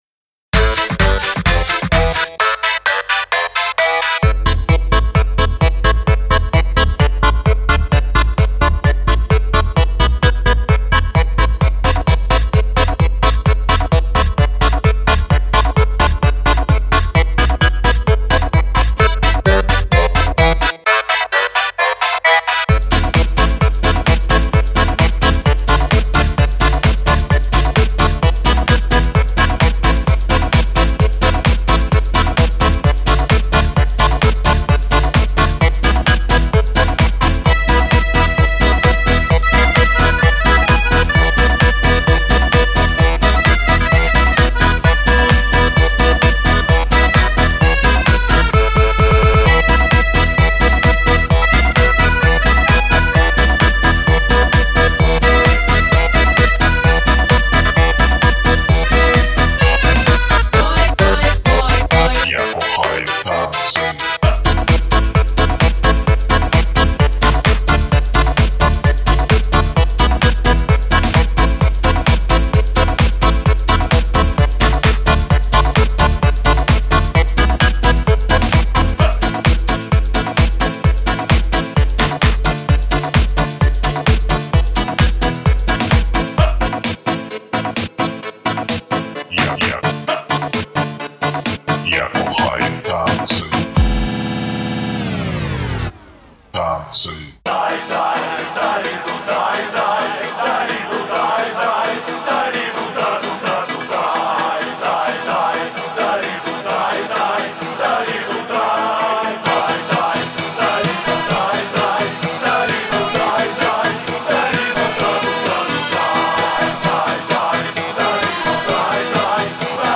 club mp3